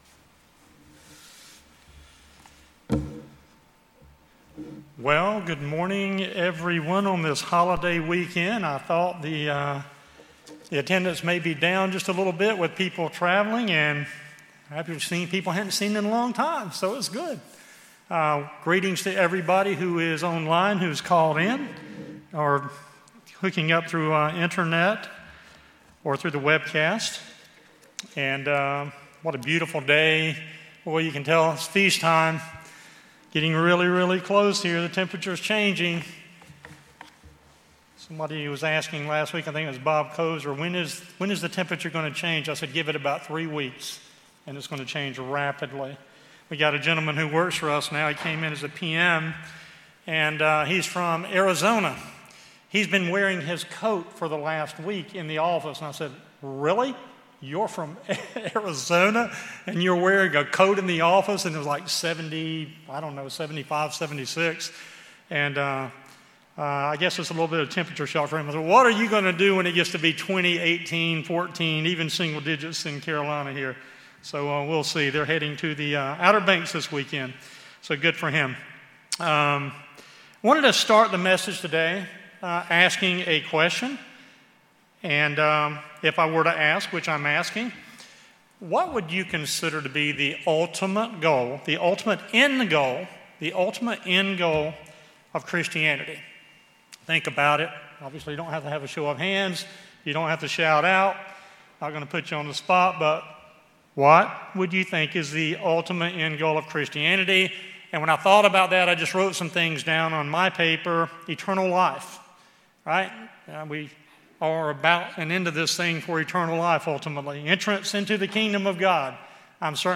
This sermon considers GOD’s very Own ‘Ultimate End Goal’ for all Christianity as HE reveals in HIS Word!